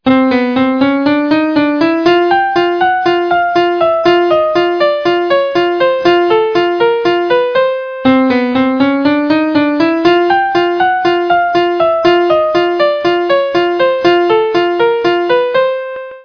A sample of a chromatic tune
Q:1/4=120
K:C